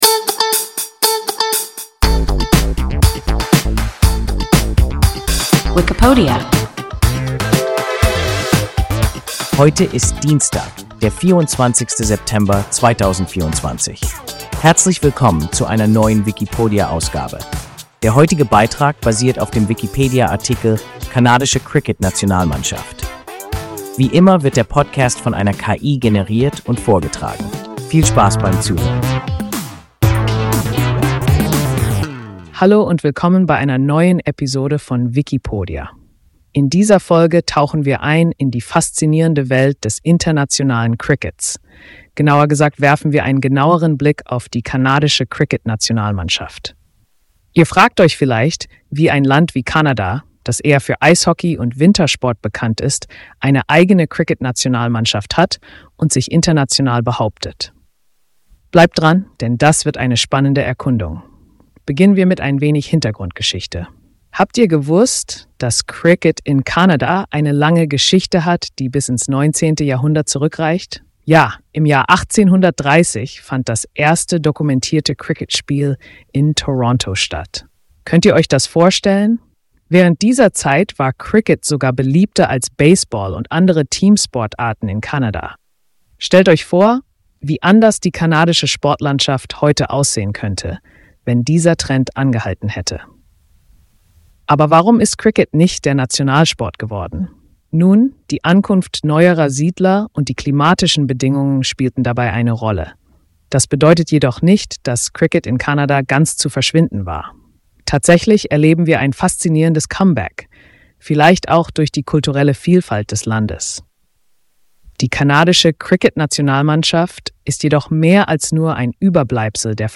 Kanadische Cricket-Nationalmannschaft – WIKIPODIA – ein KI Podcast